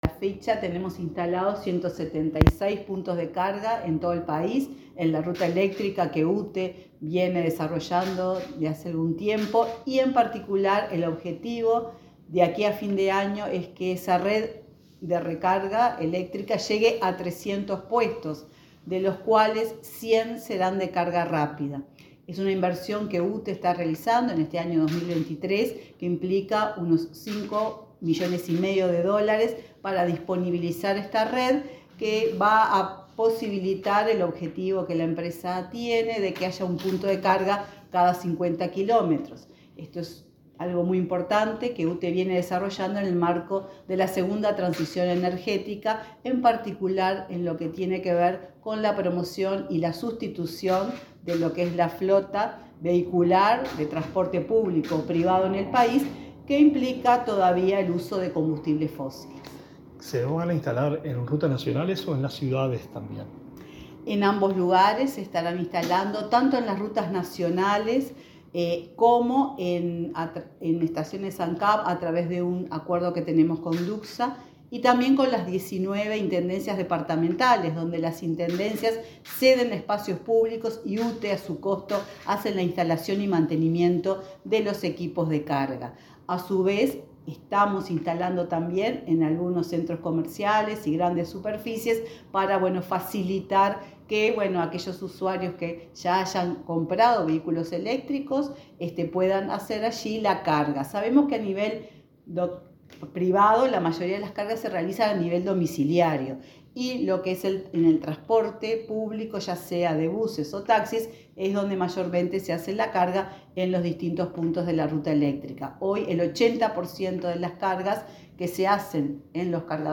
Entrevista a la presidenta de UTE, Silva Emaldi